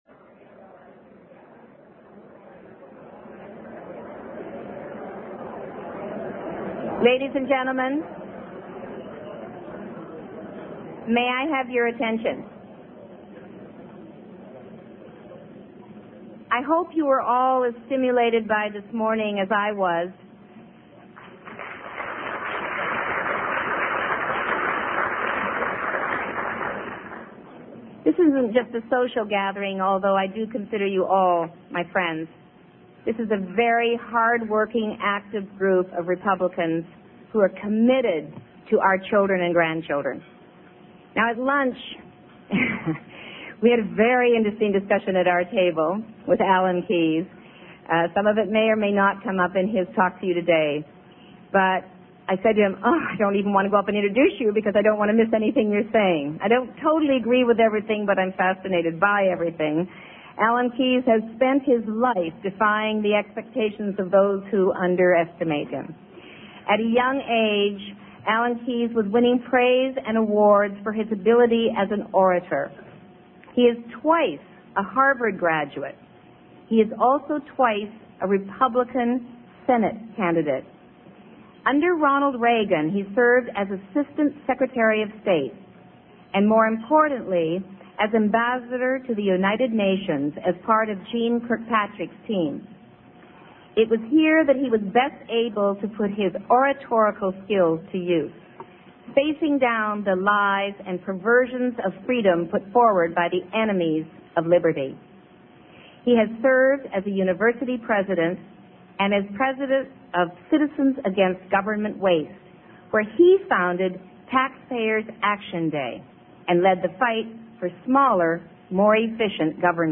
MP3 audio Speech Address to Newt Gingrich's GOPAC Alan Keyes May 1, 1995 Washington, D.C. In May of 1995, two months after announcing his candidacy for the Republican presidential nomination, Ambassador Keyes addressed a Washington, D.C. convention of the top donors and supporters of Newt Gingrich's "GOPAC."
Dr. Keyes' address to this group, and the question and answer session that followed, were one of the most dramatic and important moments in modern American politics.